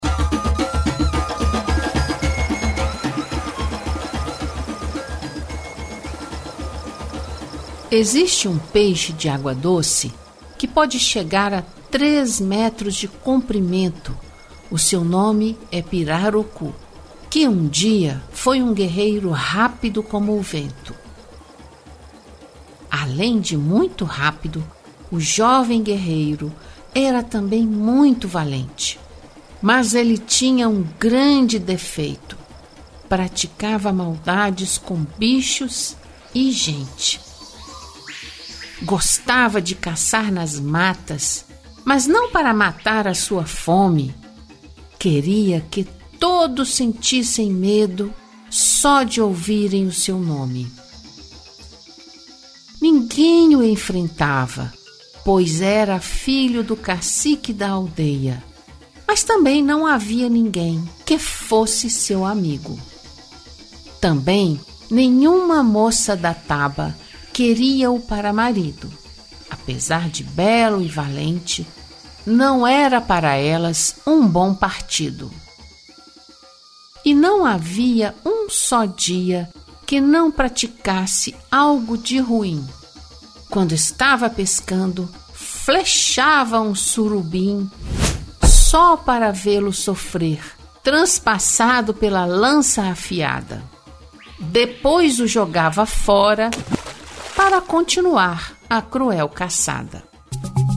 Audiolivro – A lenda do pirarucu: recontada em versos